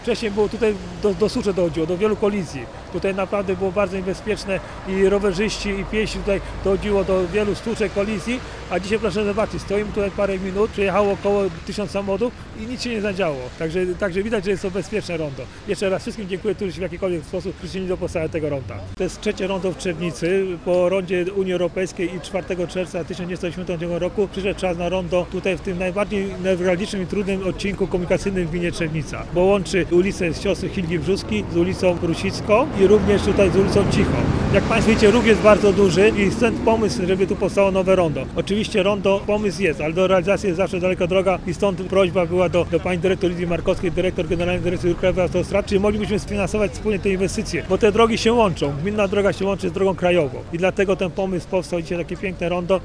Zapytaliśmy burmistrza Trzebnicy – Marka Długozimę, z czego wynikała potrzeba budowy ronda we wspomnianym miejscu.
1-Burmistrz-Marek-Dlugozima-o-pomysle-ronda.mp3